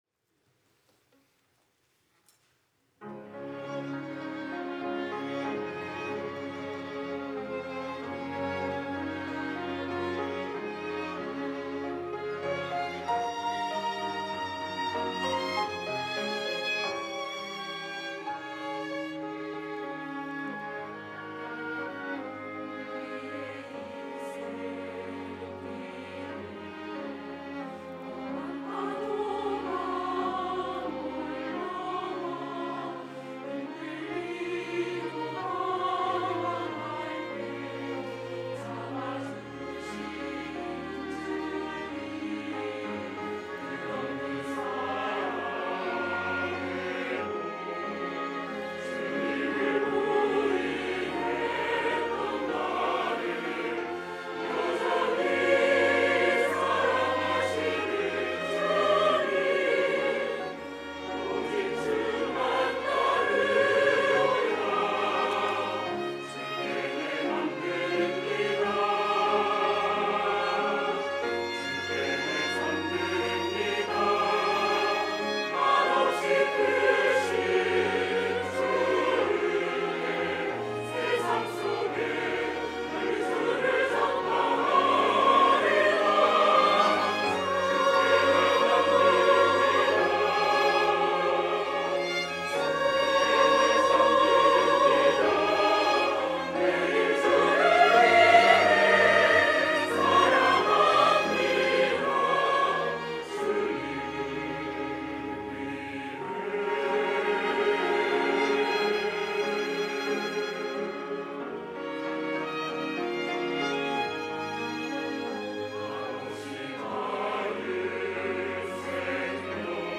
호산나(주일3부) - 주님을 위해
찬양대